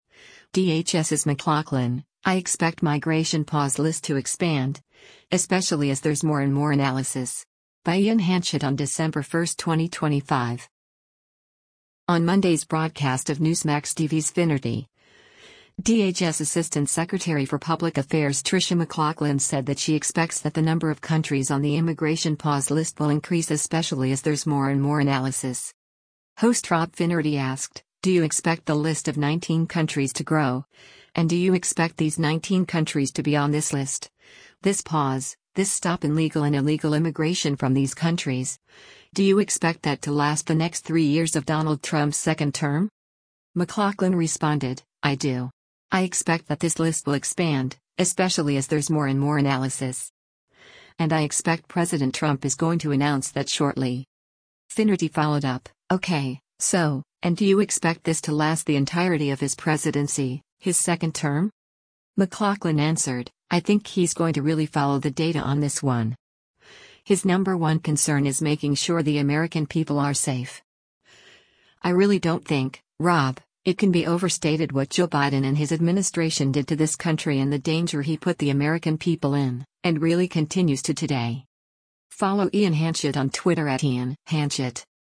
On Monday’s broadcast of Newsmax TV’s “Finnerty,” DHS Assistant Secretary for Public Affairs Tricia McLaughlin said that she expects that the number of countries on the immigration pause list will increase “especially as there’s more and more analysis.”